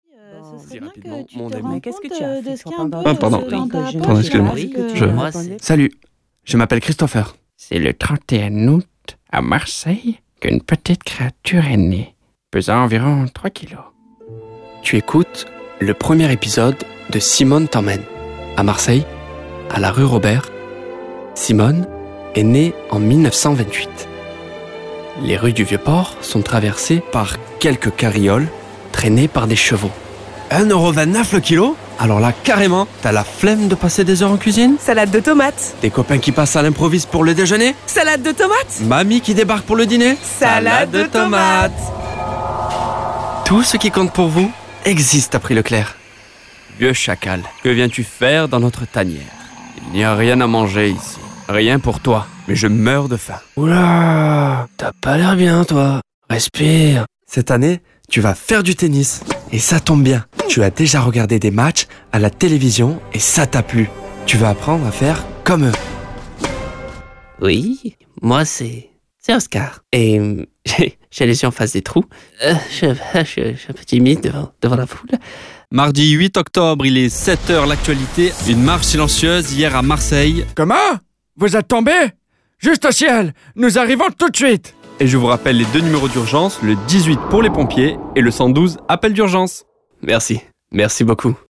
Bande démo voix off